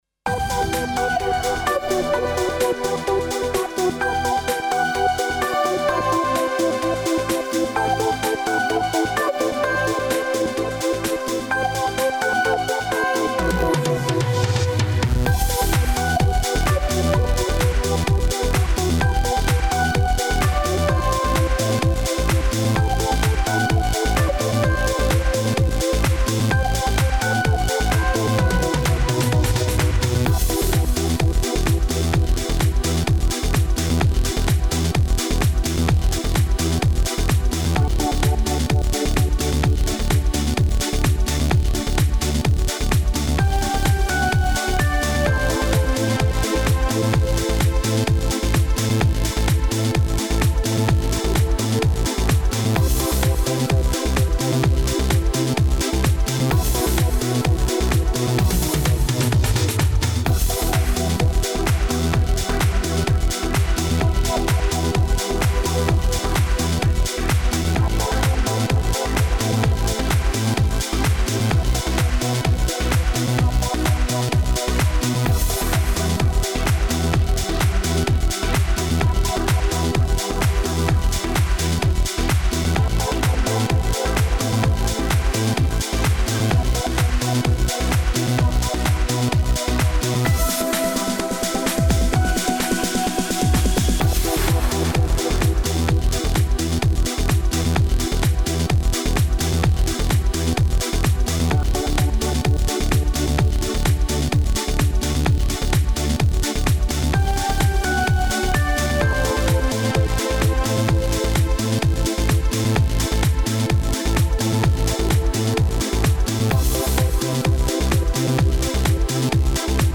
минусовка версия 22040